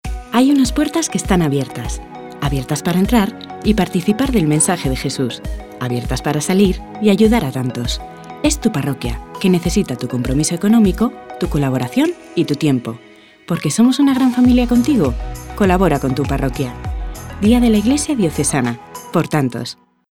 Cuñas de Radio -  Iglesia Diocesana 2017